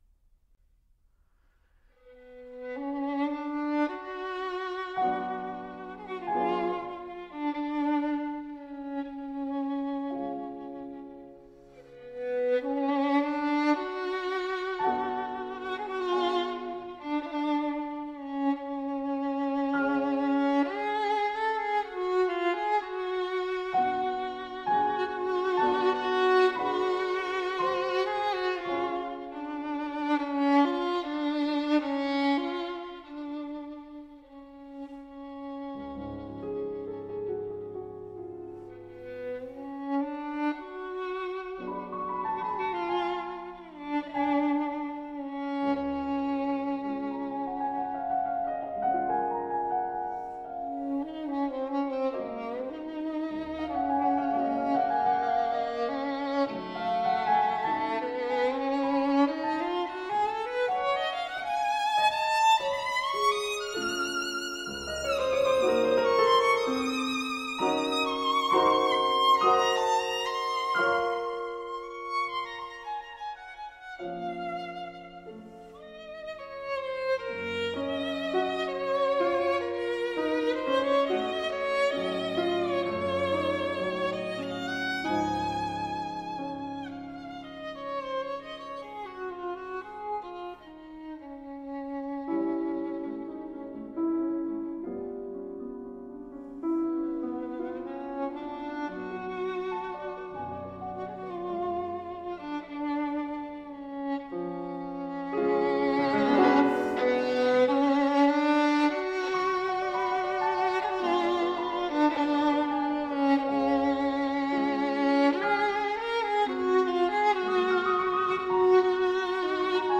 Sonata for Violin and Piano in d minor